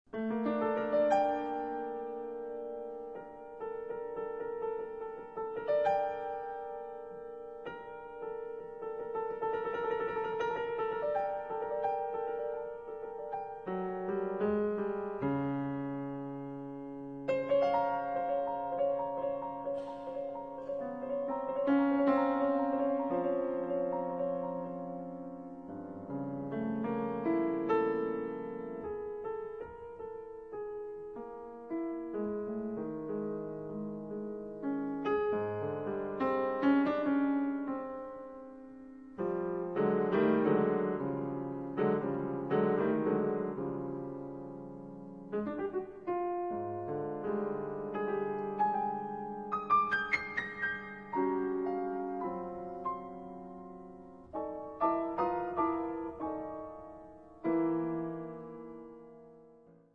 Composer, conductor, band leader, jazz pianist